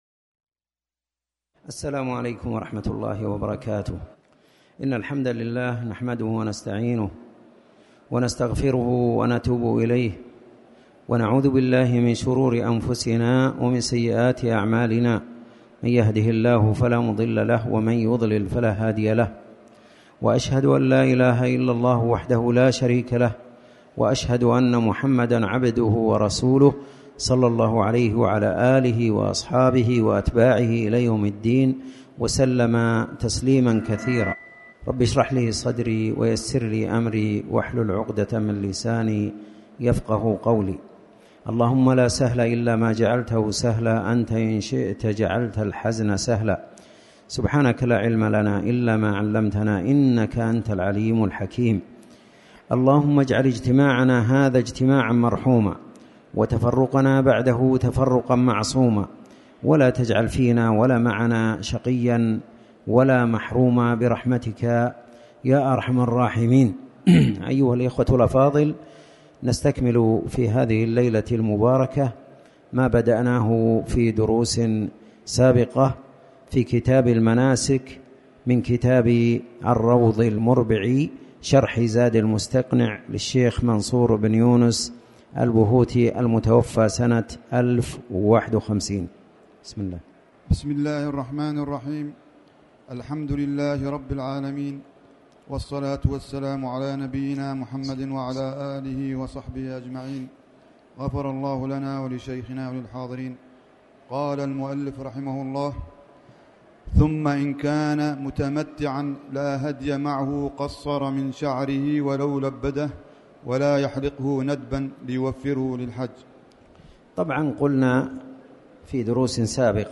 تاريخ النشر ٣ صفر ١٤٣٩ هـ المكان: المسجد الحرام الشيخ